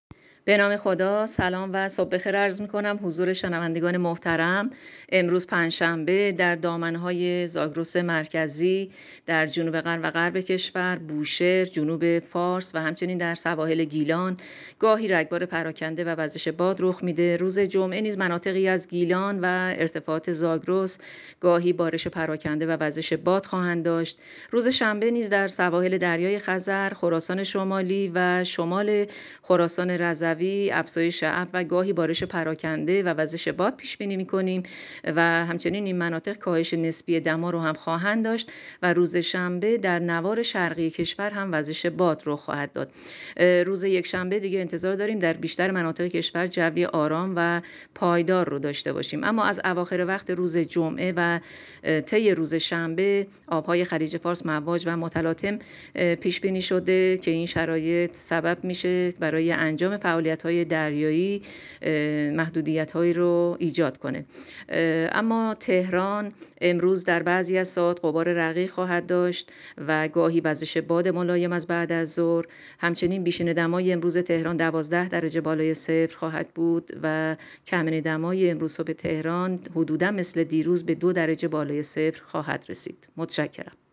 گزارش رادیو اینترنتی پایگاه‌ خبری از آخرین وضعیت آب‌وهوای ۱۵ آذر؛